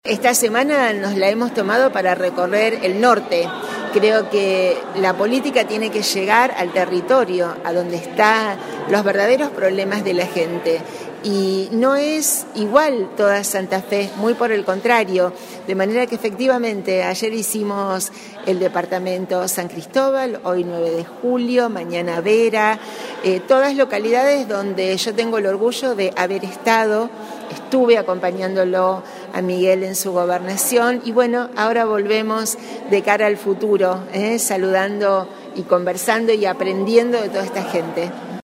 En diálogo con Periódico 9 de Julio, hizo referencia a la semana de recorrida por el norte provincial: